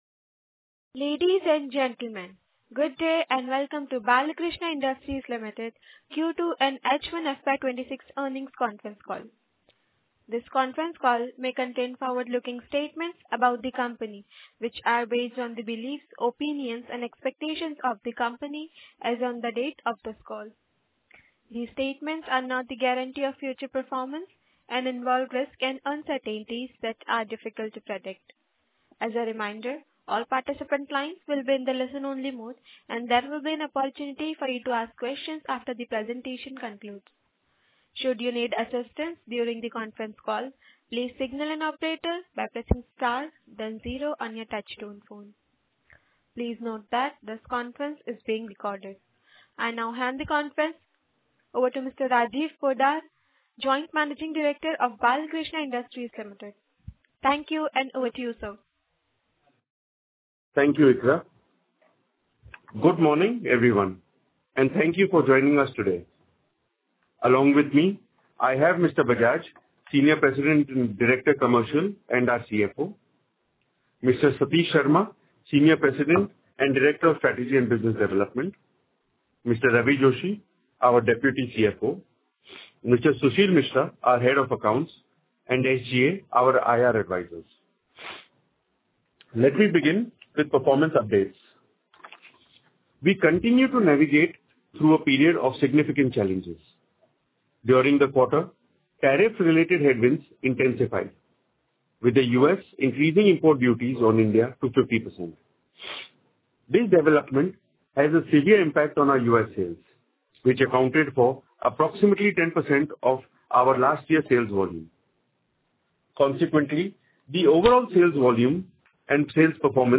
Audio recordings of conference Call dated 1.11.2025